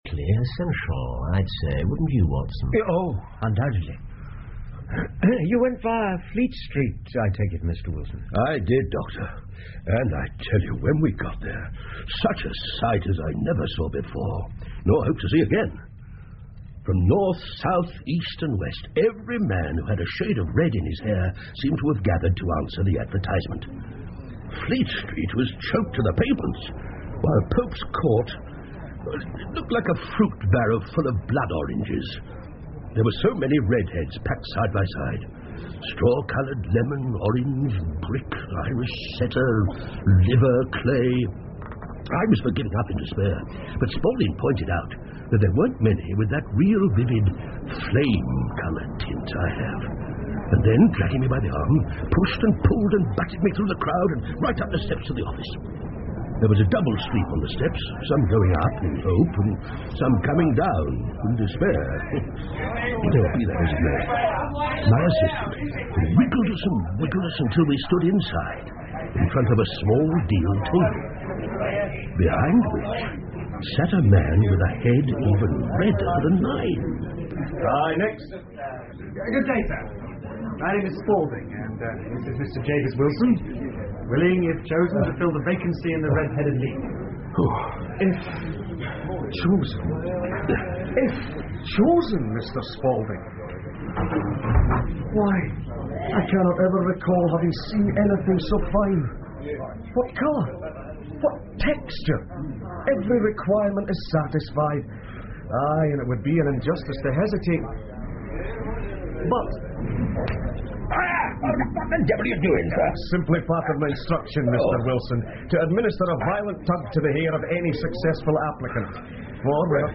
福尔摩斯广播剧 The Red Headed League 3 听力文件下载—在线英语听力室